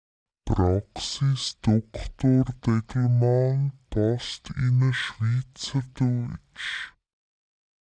As soon as I add a second agent within my squads workflow, the language gets distorted.
I use azure -> "Leni" as this is the only correct swiss german pronounciation available.